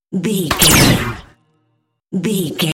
Dramatic stab laser
Sound Effects
Atonal
heavy
intense
dark
aggressive
hits